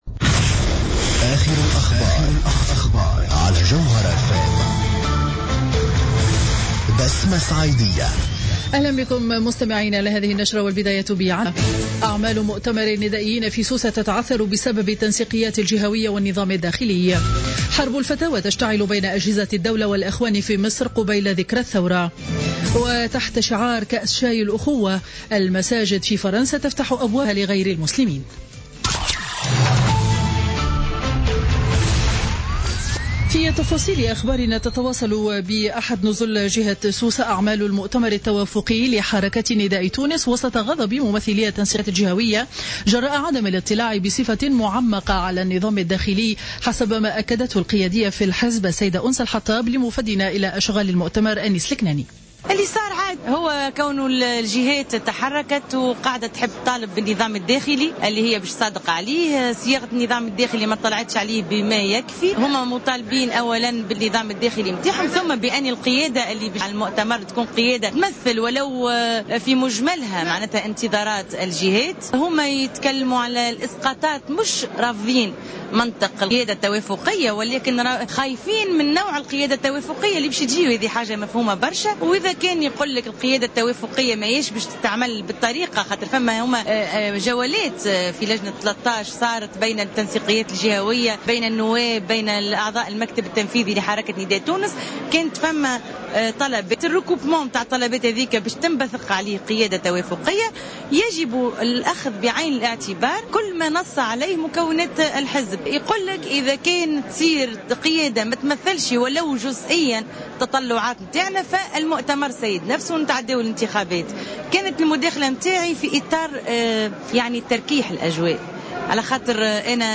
Journal Info 12H00 du Dimanche 10 Janvier 2016